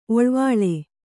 ♪ oḷvāḷe